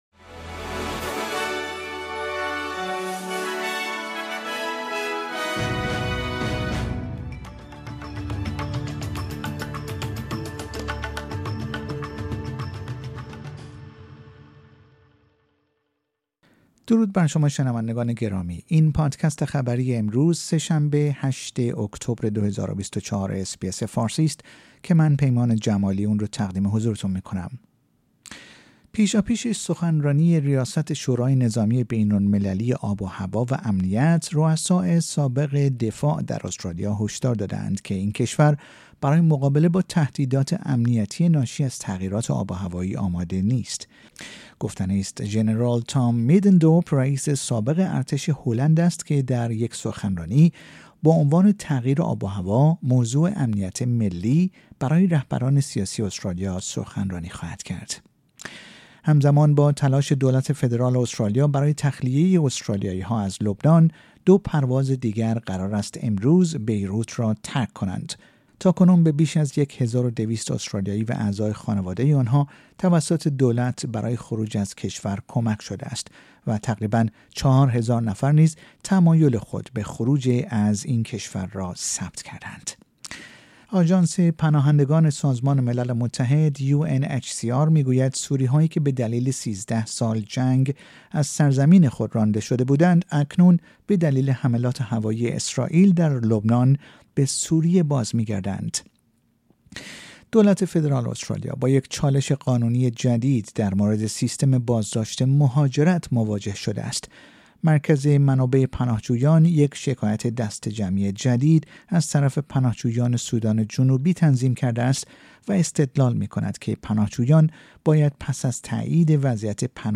در این پادکست خبری مهمترین اخبار استرالیا در روز سه شنبه ۸ اکتبر ۲۰۲۴ ارائه شده است.